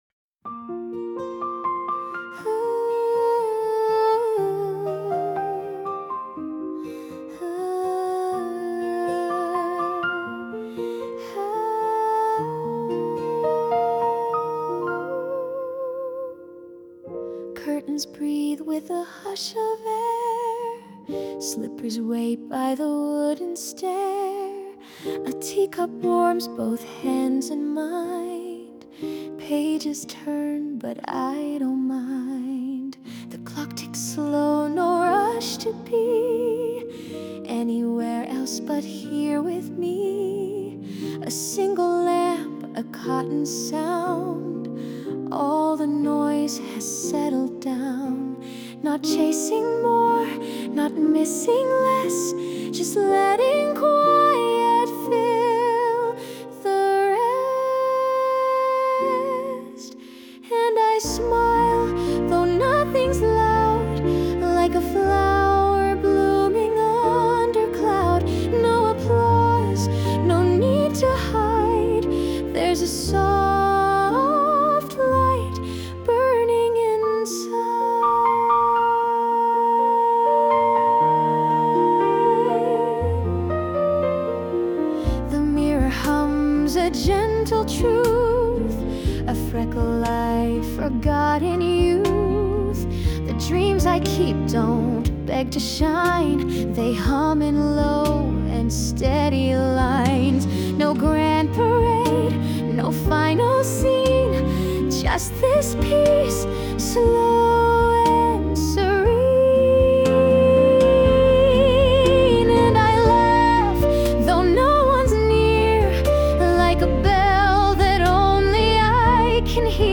洋楽女性ボーカル著作権フリーBGM ボーカル
著作権フリーオリジナルBGMです。
女性ボーカル（洋楽・英語）曲です。
静かに語るミュージカルのような曲を目指しました♪♪
そんな穏やかで明るい幸せを、やさしく描いた楽曲です。